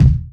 Kick25.wav